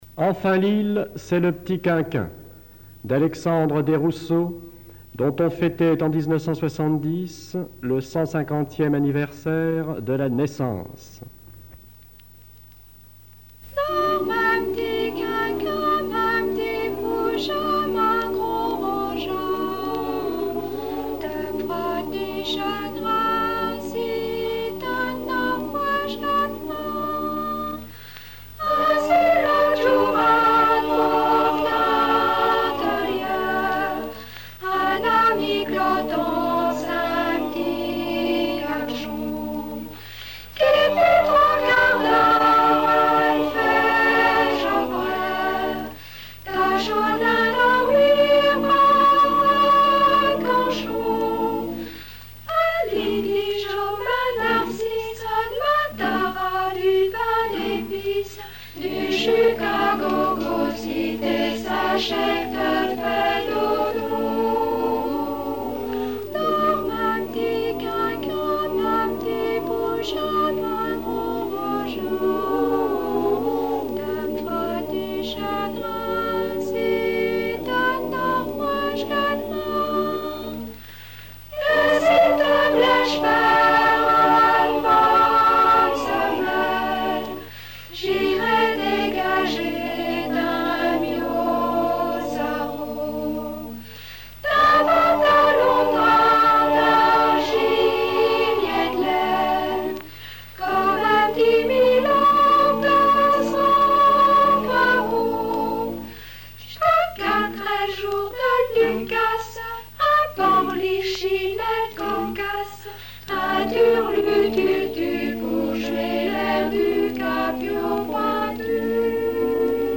Dors min p'tit quinquin Détails de l'archive Titre Dors min p'tit quinquin Origine du titre : Enquêté Note service technique du CRDP de Lille, journée amicale des directeurs de CRDT. Choeur le Madrigal de Lille
Berceuses diverses
Pièce musicale inédite